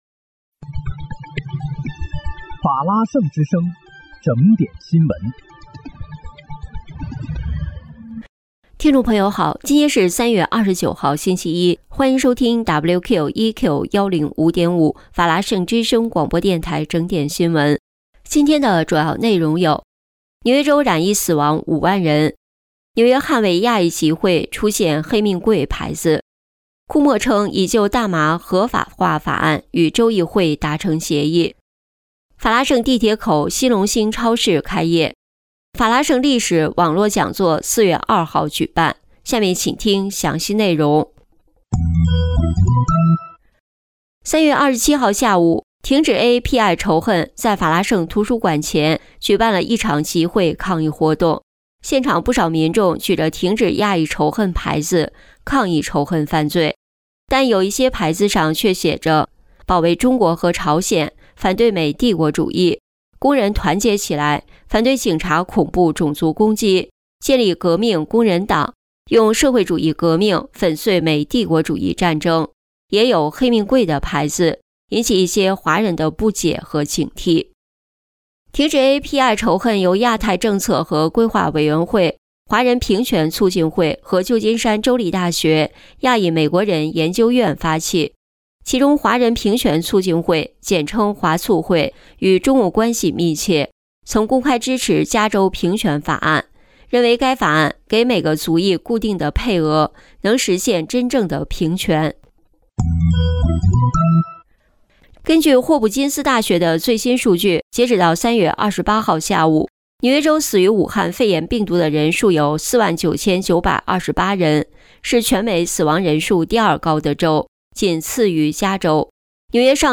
3月29日（星期一）纽约整点新闻